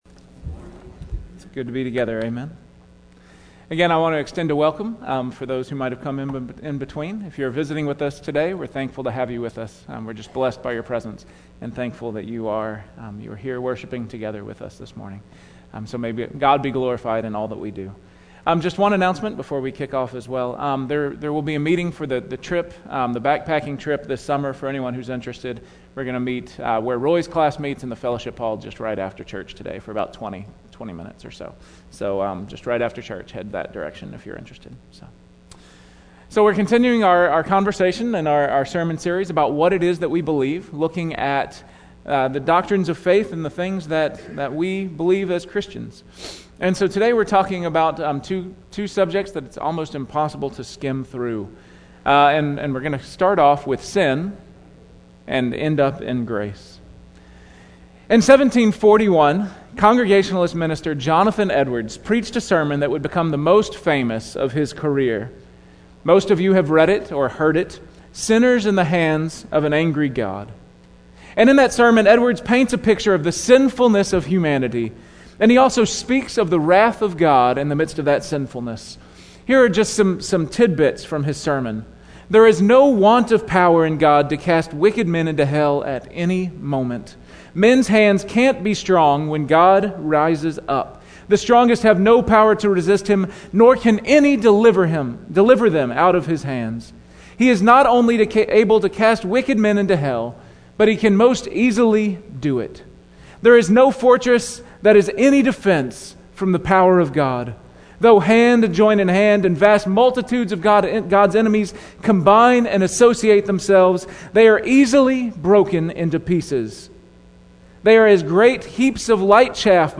Weekly Sermon Audio “What We Believe About…